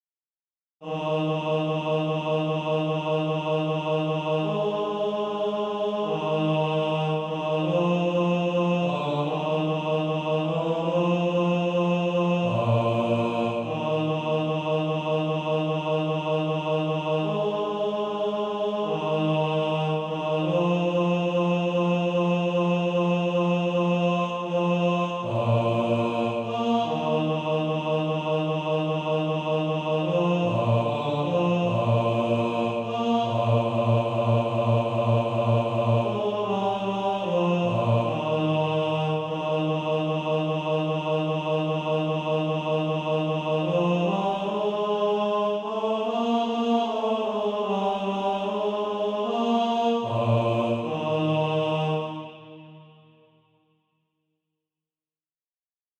Bass Track.
Practice then with the Chord quietly in the background.